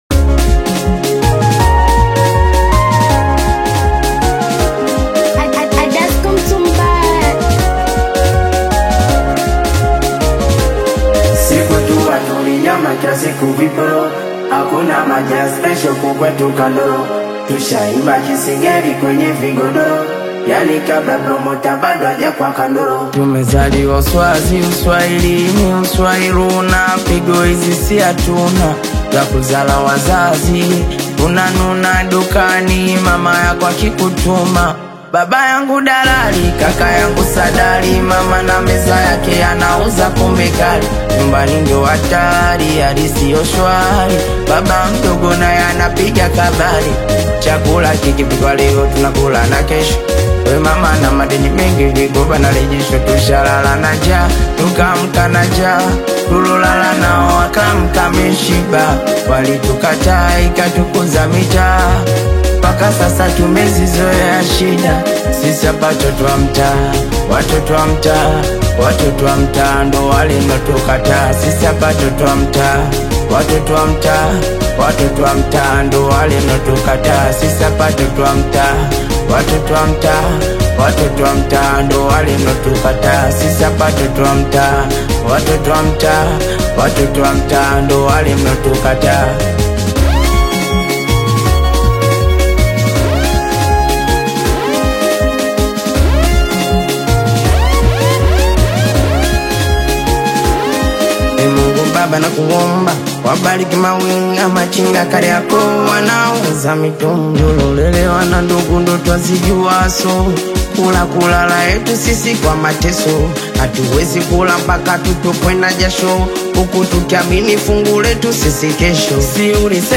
energetic Tanzanian urban single